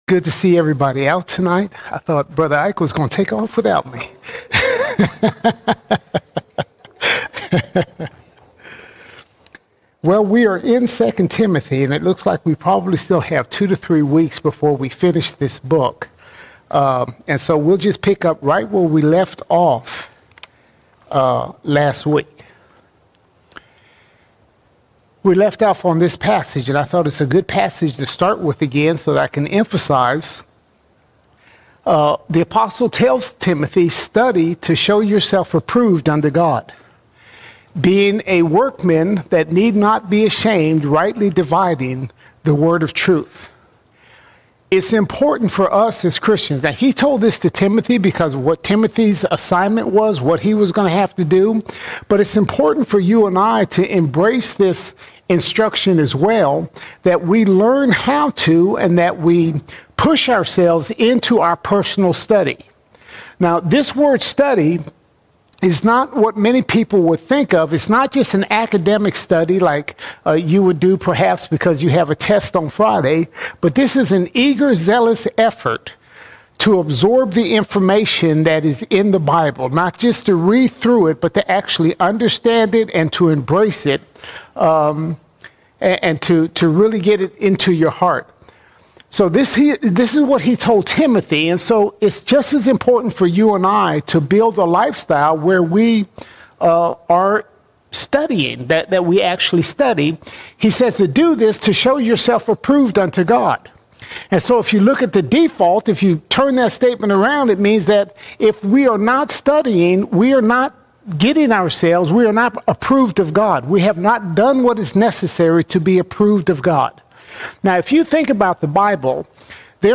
29 August 2025 Series: 1 & 2 Timothy All Sermons 2 Timothy 2:15 - 3:13 2 Timothy 2:15 – 3:13 We have been called to be diligent workers who rightly handle the Word of truth, standing firm in sound doctrine.